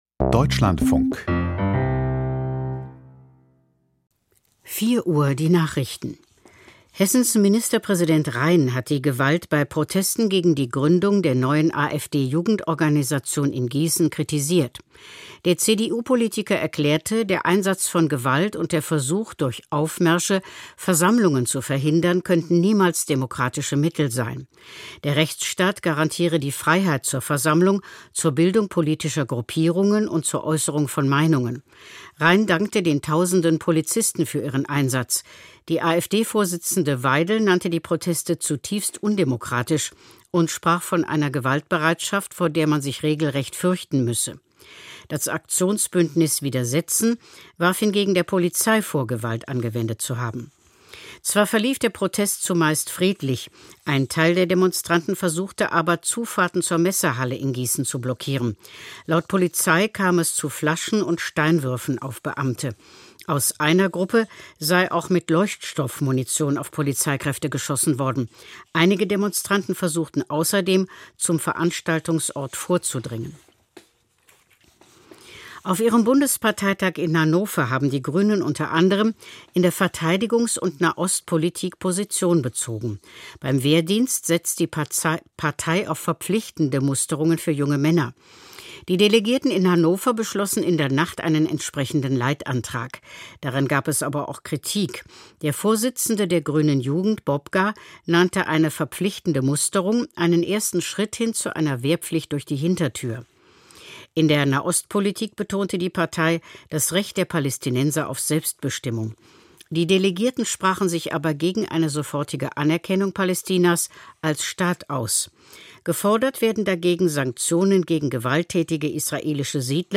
Die Nachrichten vom 30.11.2025, 04:00 Uhr
Die wichtigsten Nachrichten aus Deutschland und der Welt.